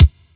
Bp Bd.wav